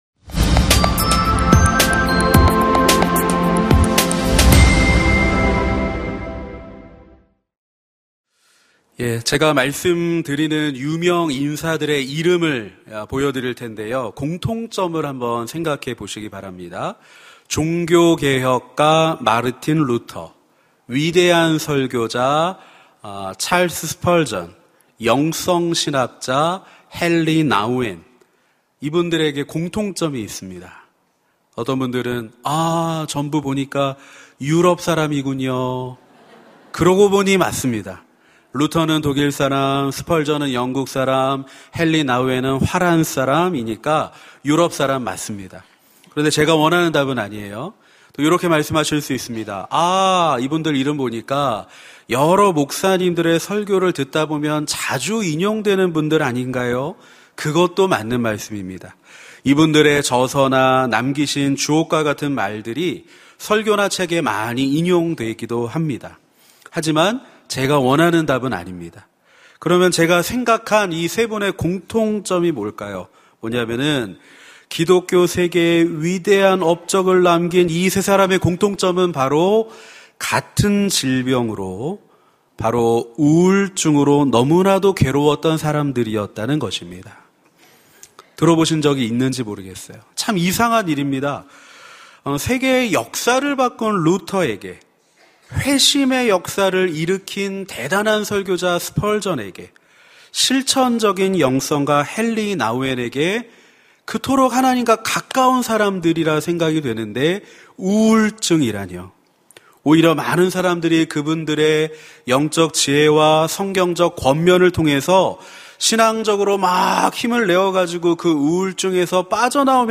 설교 : 시니어예배